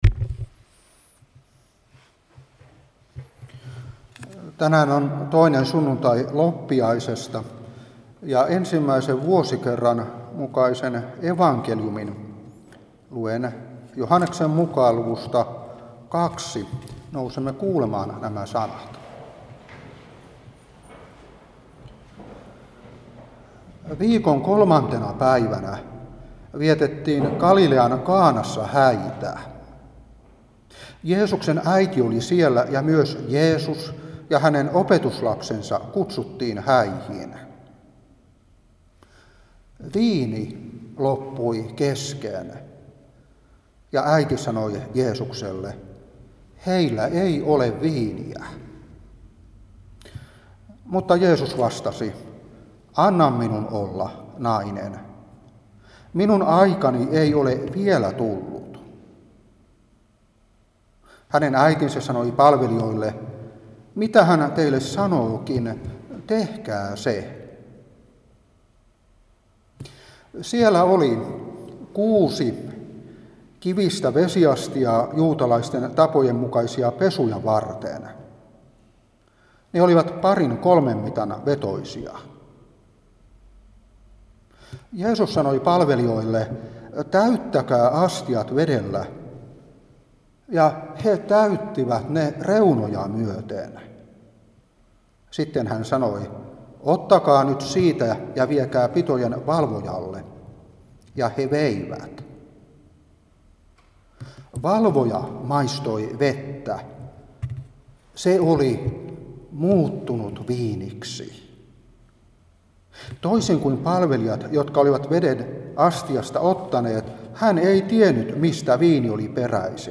Saarna 2022-1.